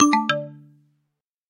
Звуки подключения, отключения